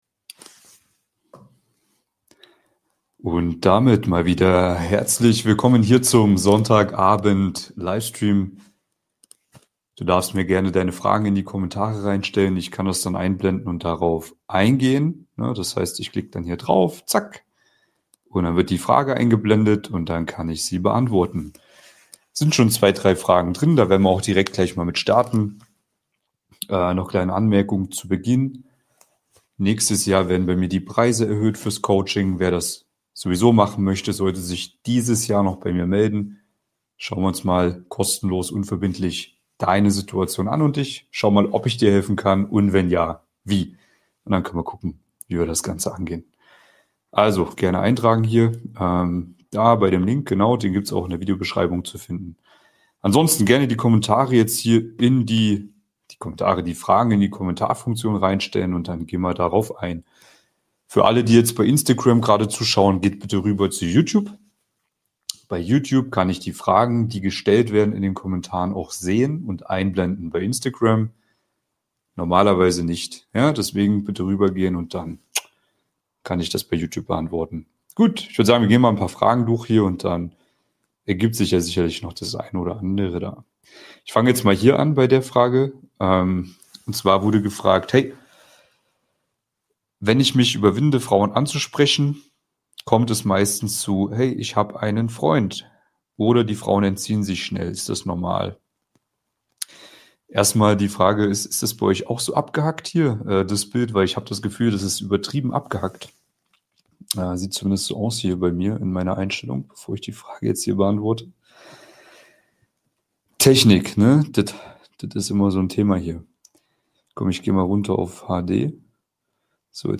Live: Frauen-Psychologie entschlüsselt - Endlich erfolgreich daten! ~ Mission Traumfrau – Für Männer mit Anspruch Podcast